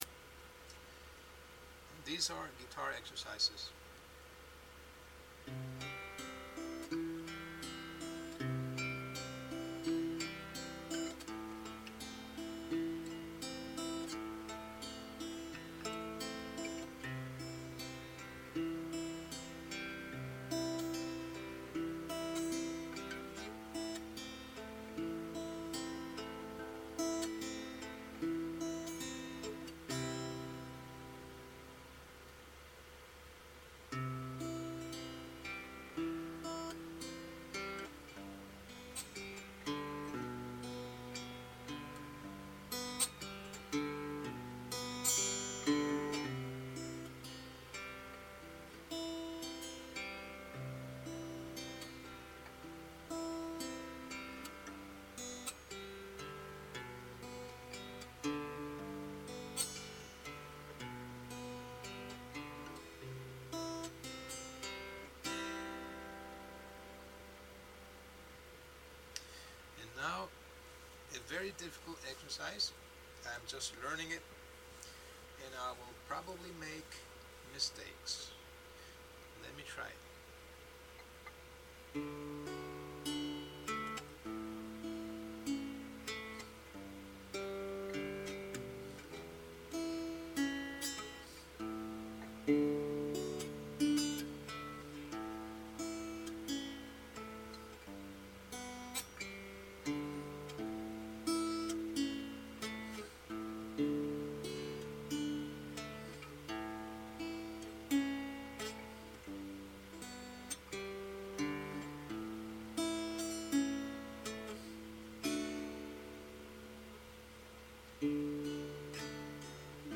Guitar Exercise 2